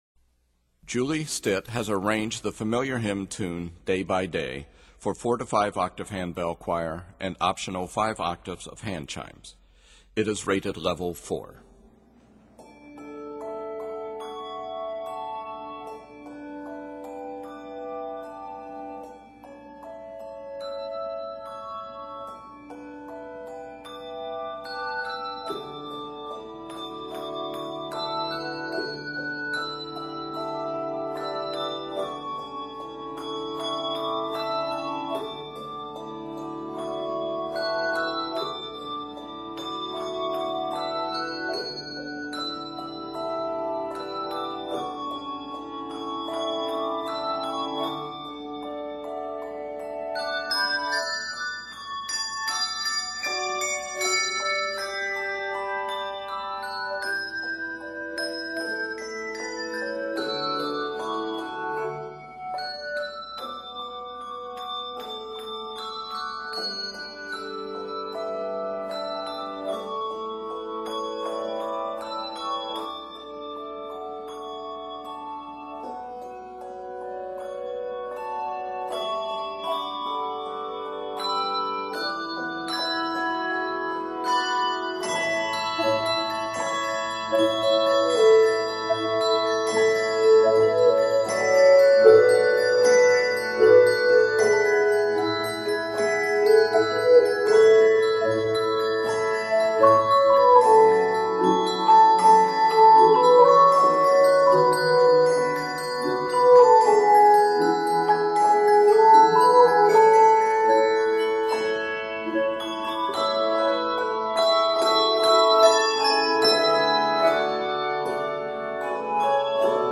Octaves: 4-5